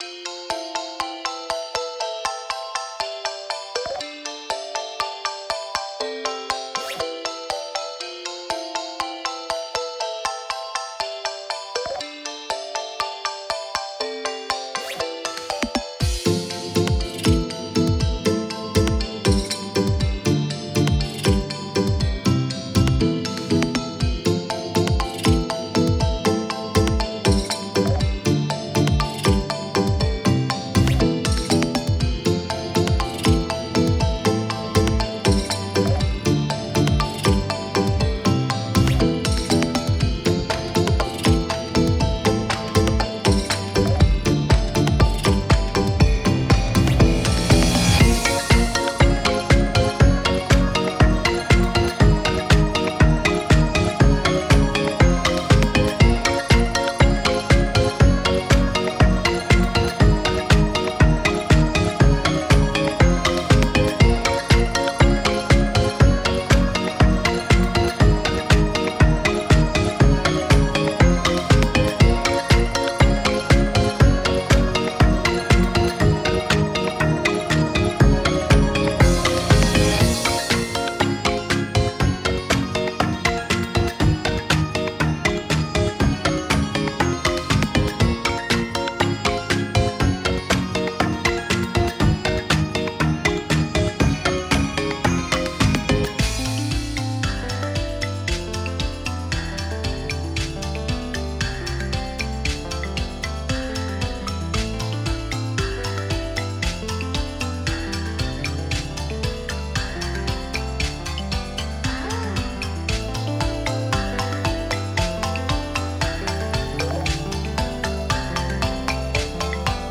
off vocal音源↓
↑多少語りとかの声が入ってる方